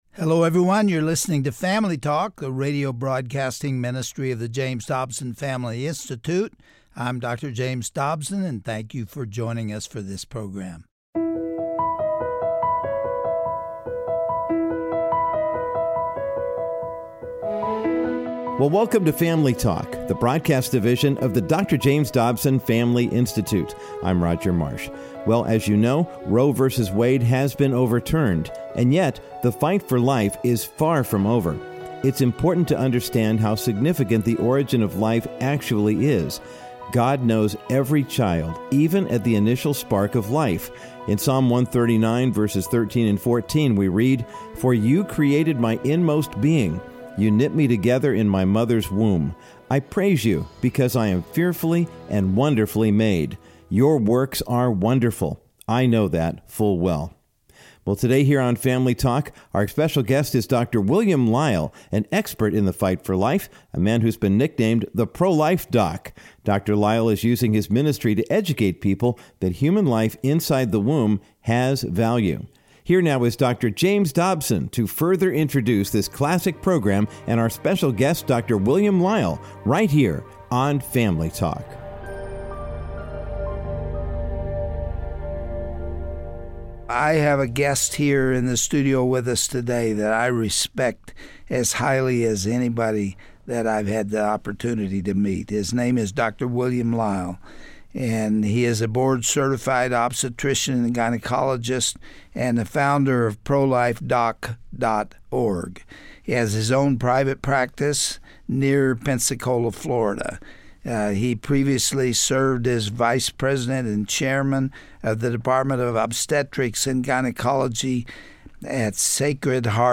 Dr. James Dobson interviews pro-life advocate